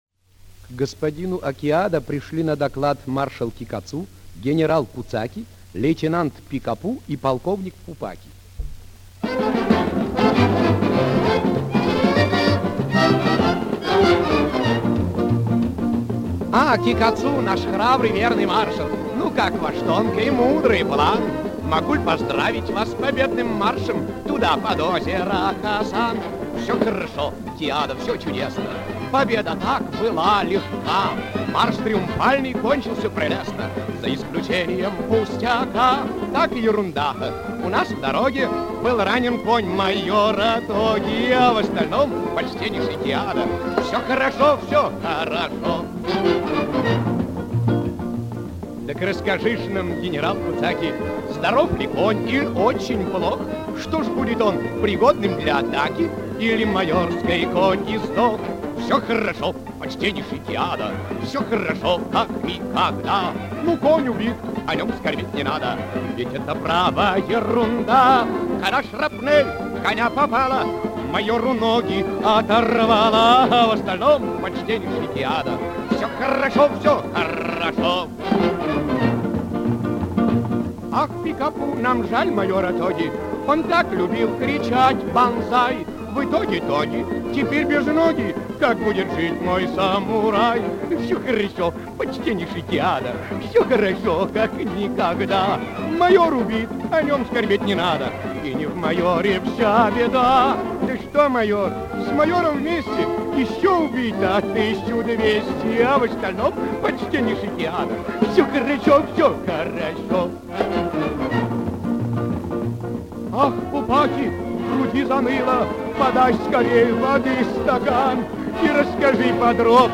Сатирическая песня
джаз-орк.